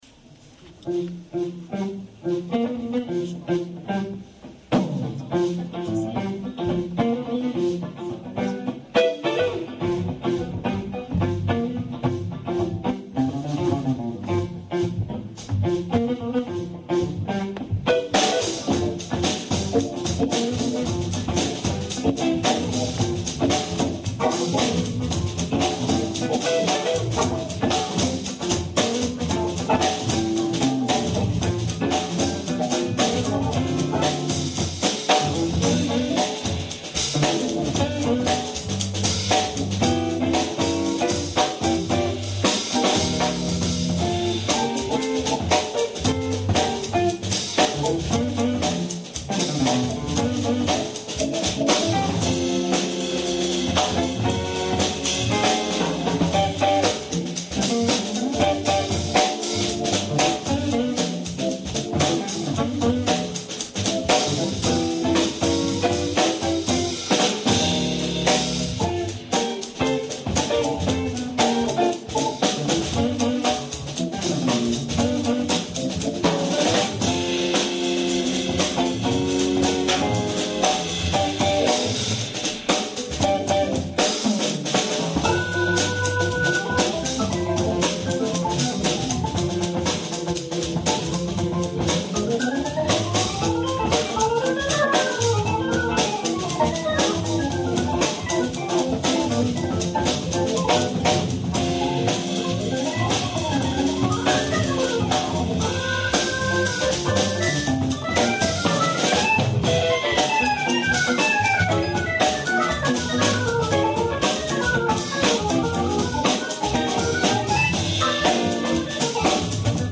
Un gentil spectateur a enregistré la totalité du concert de vendredi dernier sur un minidisc....la qualité n'est pas au rendez vous mais c'est ecoutable
guitare
batterie
La batterie couvre pas mal..mais bon, c'est du live!